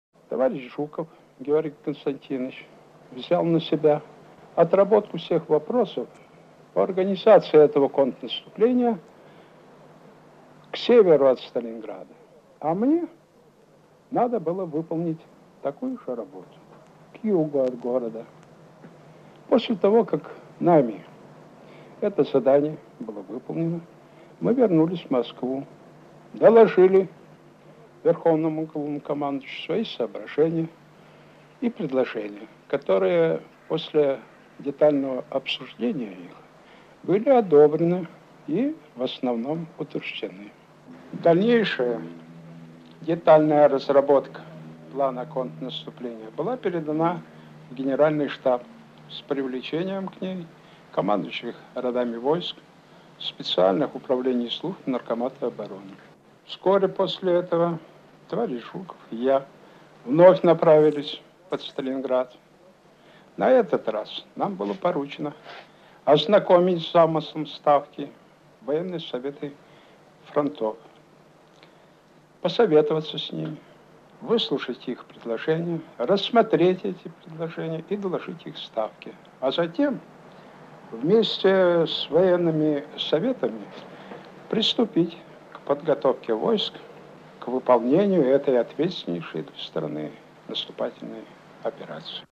Маршал Александр Василевский вспоминает о подготовке контрнаступления под Сталинградом (Архивная запись).